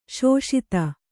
♪ śoṣita